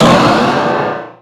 Cri de Méga-Latias dans Pokémon Rubis Oméga et Saphir Alpha.
Cri_0380_Méga_ROSA.ogg